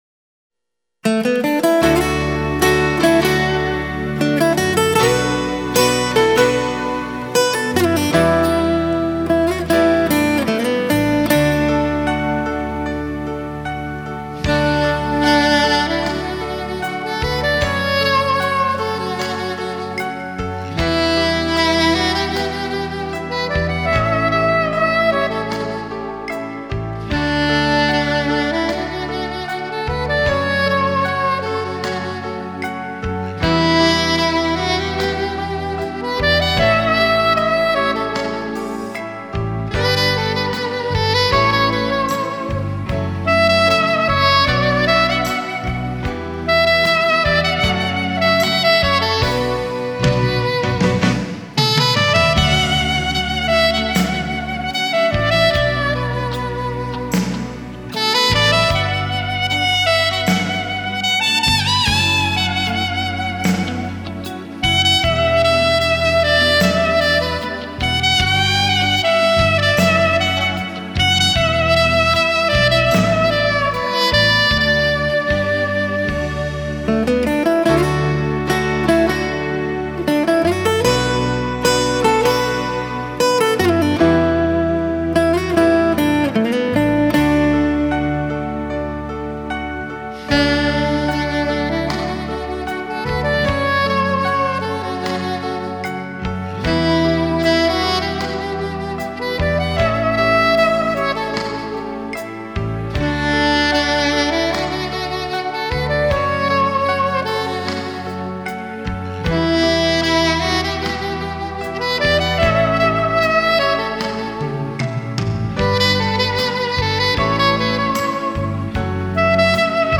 喜欢萨克斯妩媚的音色感觉很飘然...